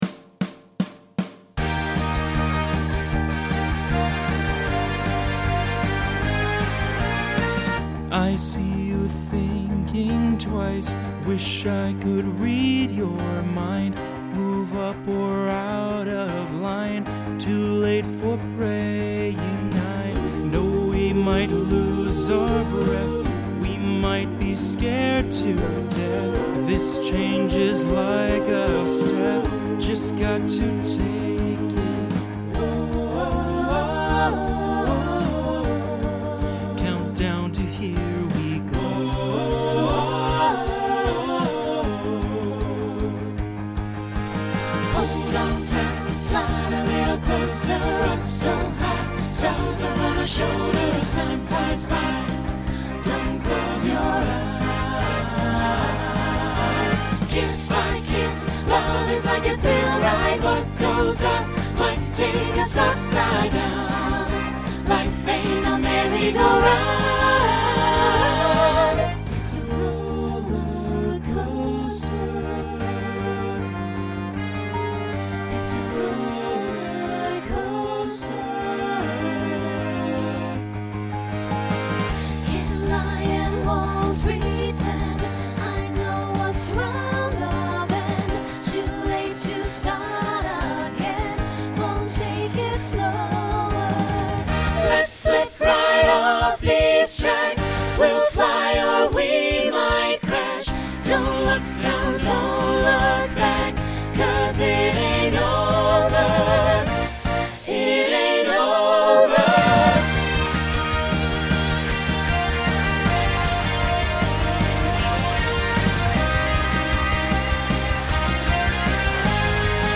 New SSA voicing for 2023.
Genre Rock Instrumental combo